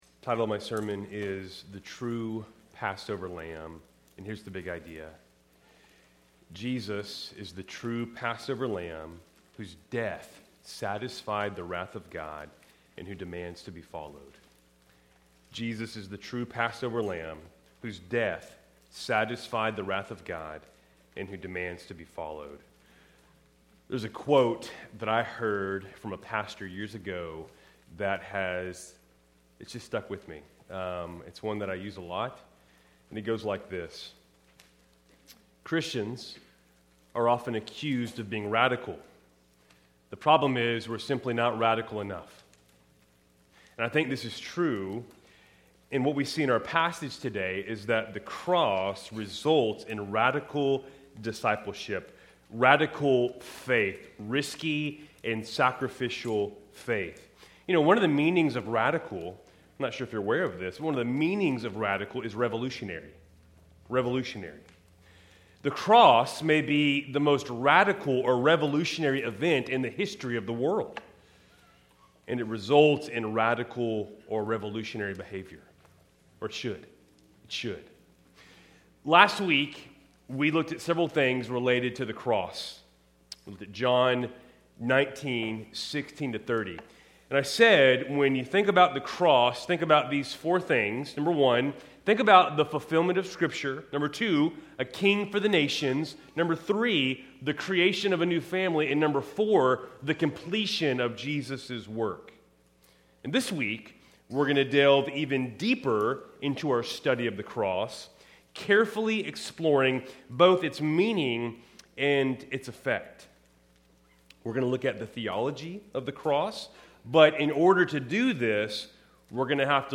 Keltys Worship Service, February 22, 2026